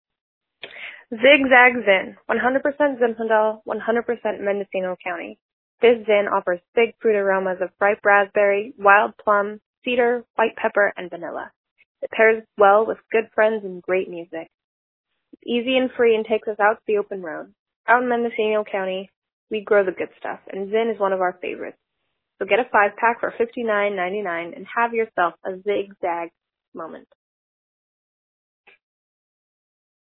Vintner Voicemail play pause A fun introduction for today's offer from Zig Zag Zinfandel Sales Stats Snapshot Quantity Breakdown 98% bought 1 2% bought 2 0% bought 3 Speed to First Woot: 14m 49.667s See full sales stats